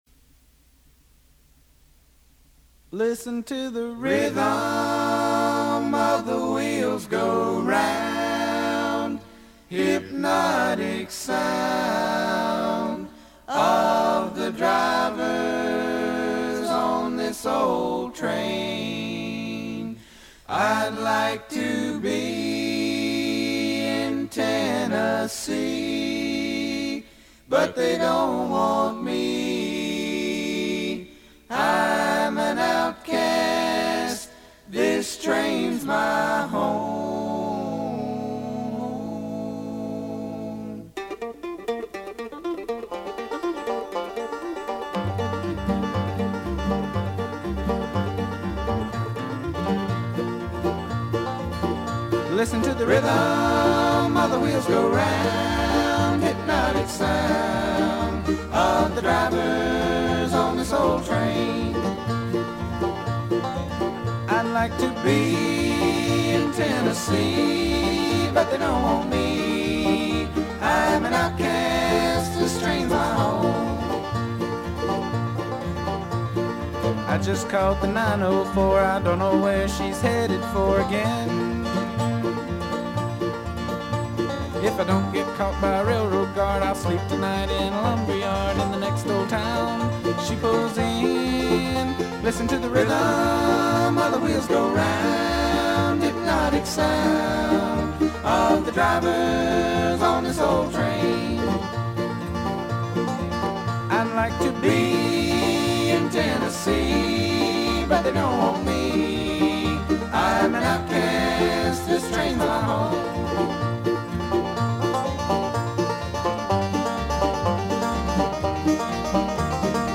guitar, vocals
bass
mandolin, vocals
banjo, vocals